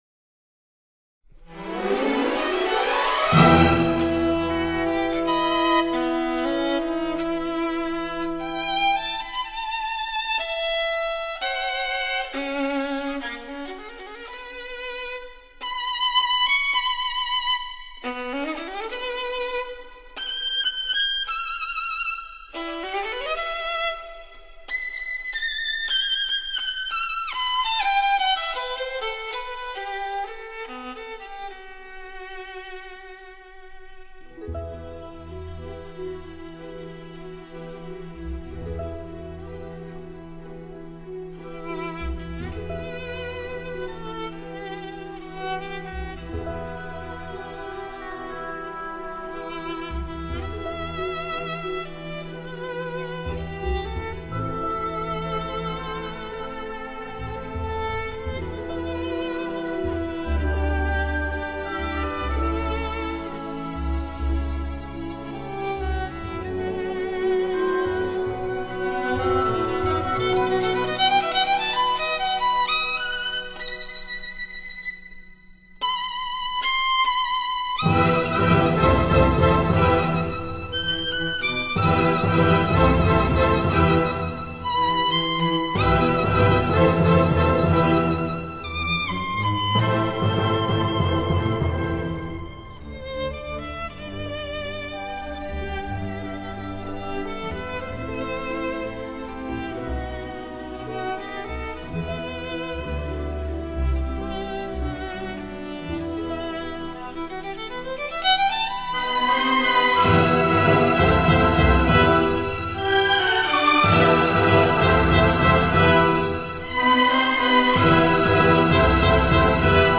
而现在DTS就能够发挥他的特点，让你得到非常充实的现场感，DTS格式的音乐更会体现其方位感和真实感。